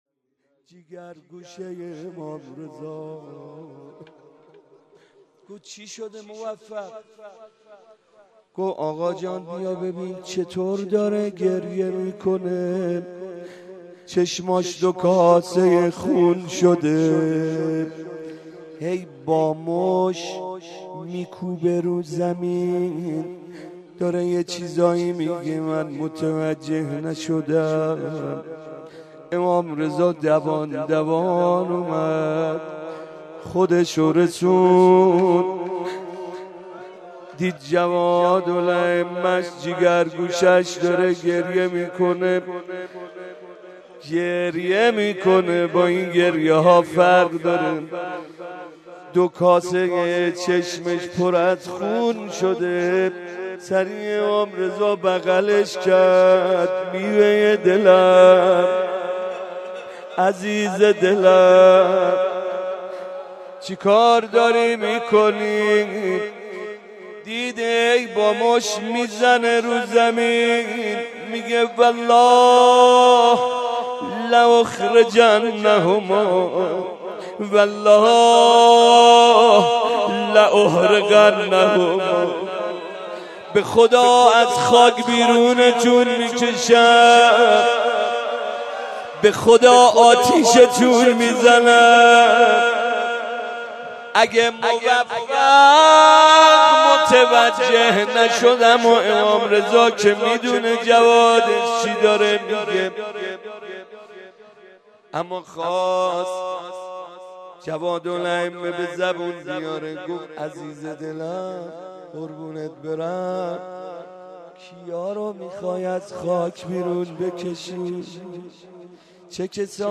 روضه
مناسبت : شب نهم رمضان
قالب : روضه